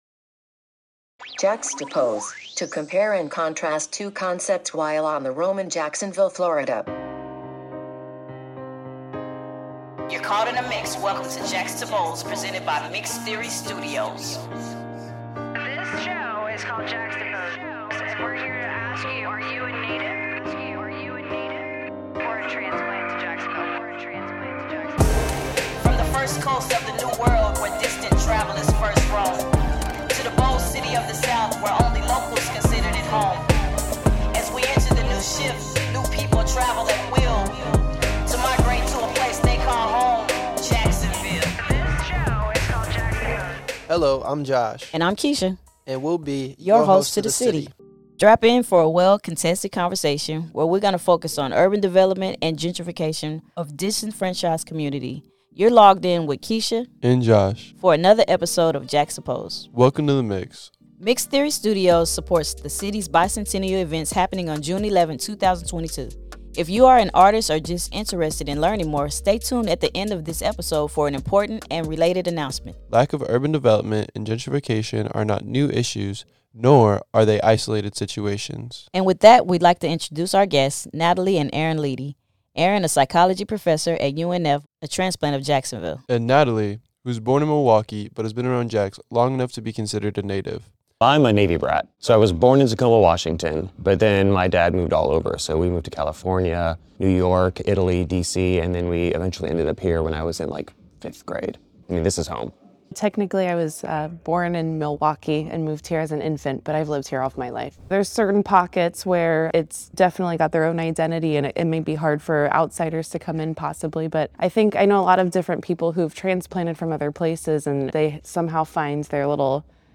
This couple discusses their views of the area and its revitalization efforts. Avoiding gentrification can be tricky. Existing residents and historic buildings should be at the forefront of everyone's consideration.